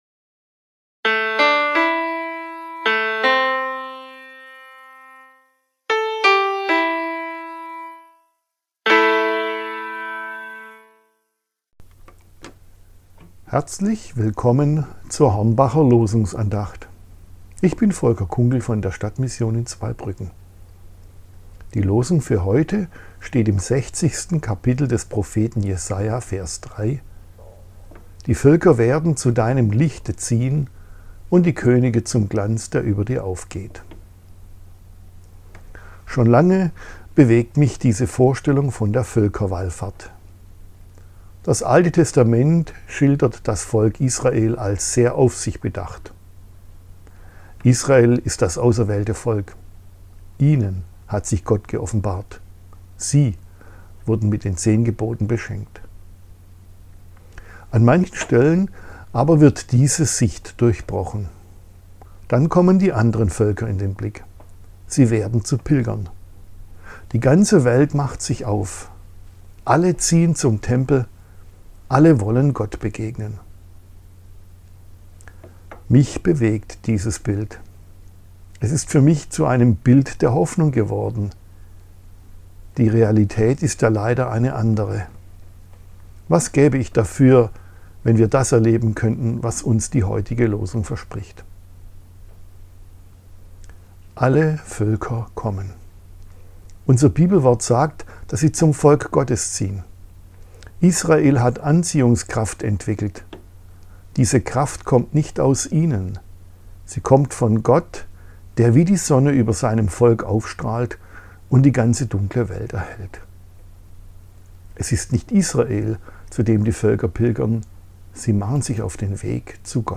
Losungsandachten – Seite 81 – Prot.